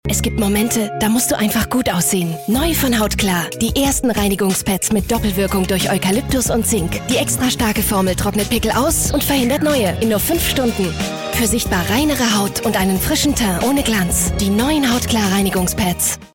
deutsche, junge Sprecherin. Ihre Stimme klingt lebendig, freundlich, jung, frisch und klar.
Sprechprobe: Werbung (Muttersprache):
german female voice over artist, young voice